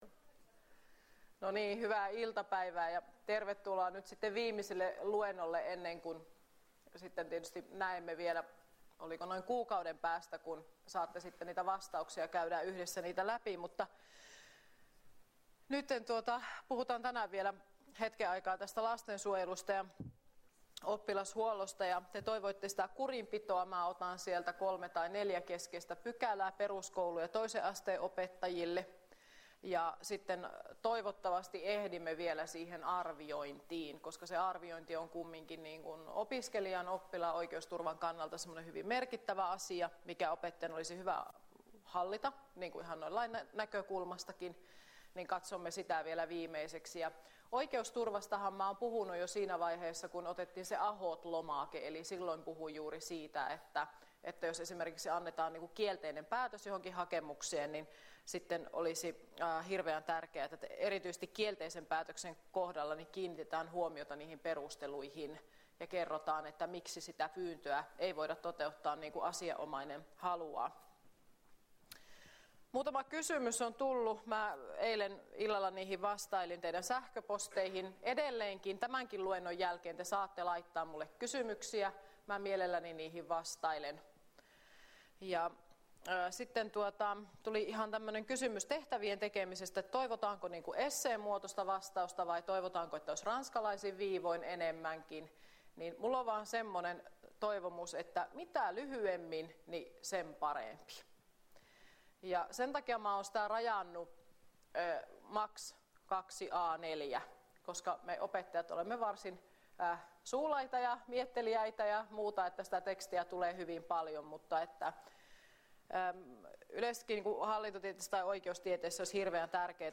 Luento 16.2.2015 — Moniviestin